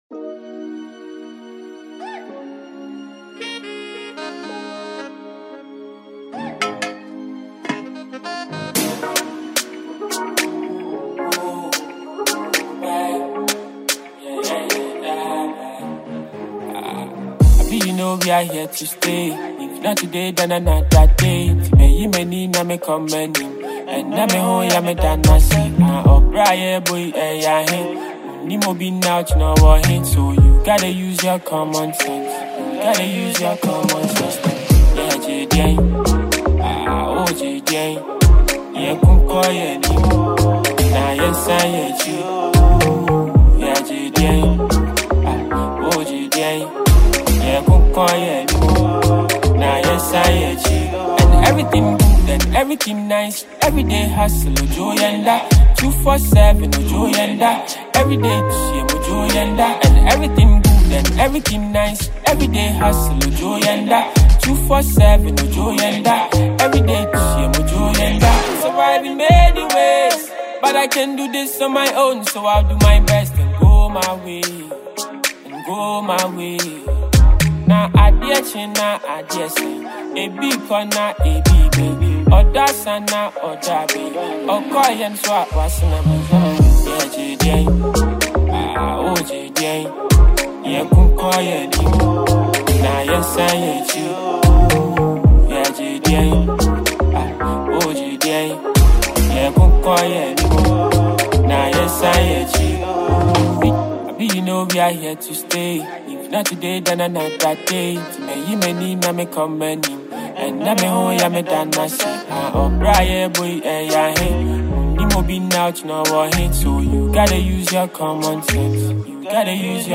Ghana Music
soulful voice effortlessly conveys vulnerability and emotion
Through his emotive voice and relatable lyrics